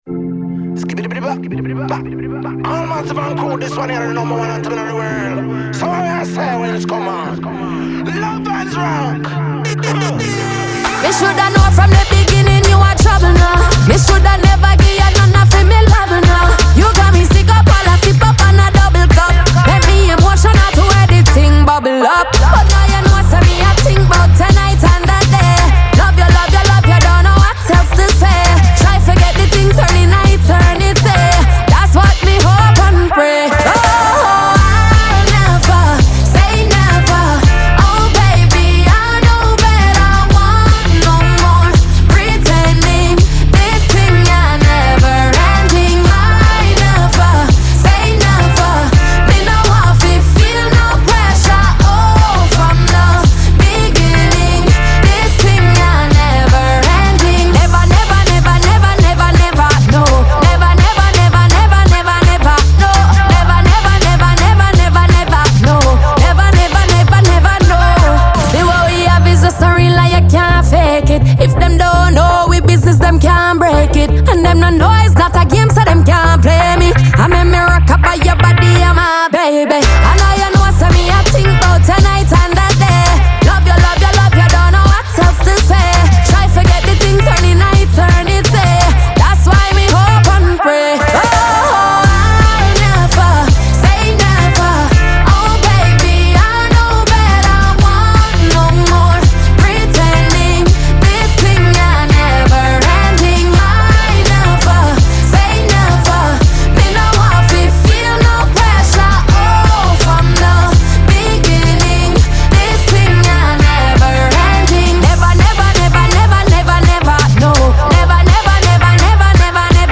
• Жанр: Pop, Reggae